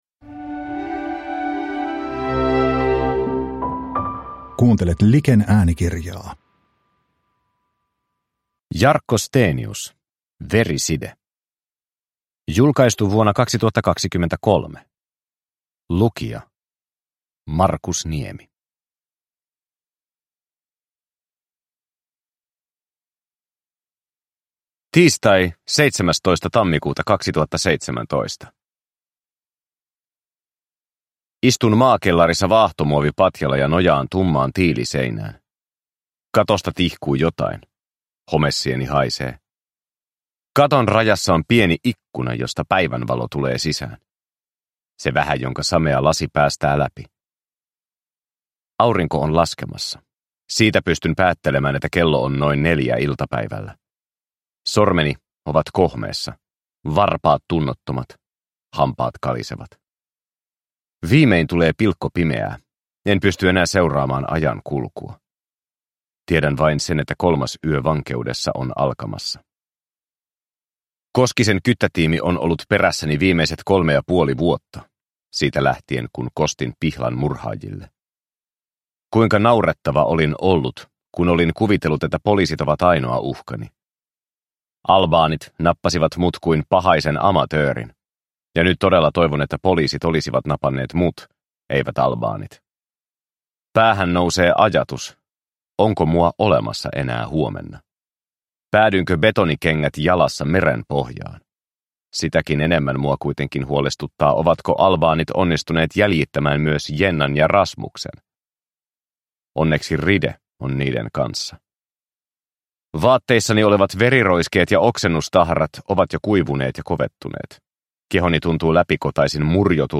Veriside (ljudbok